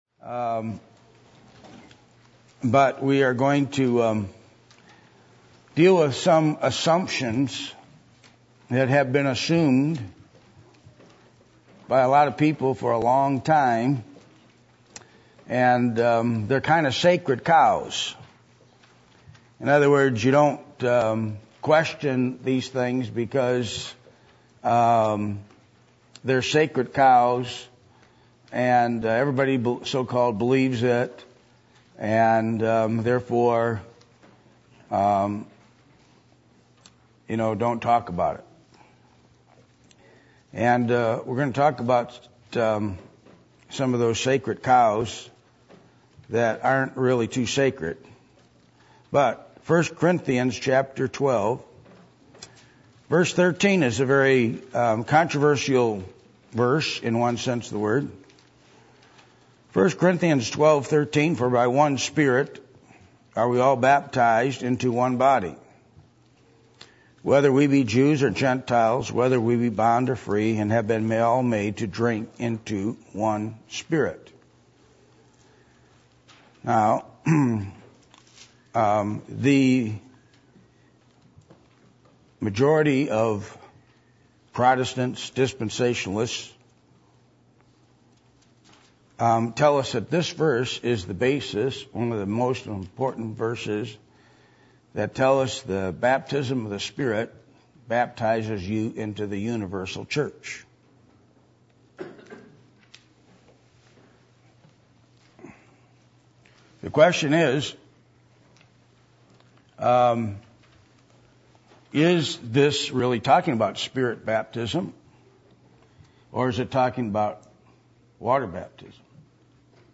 1 Corinthians 12:1-31 Service Type: Midweek Meeting %todo_render% « God’s Answer to False Religion What Is The Challenge Of Faith?